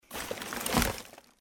trashplastic3.mp3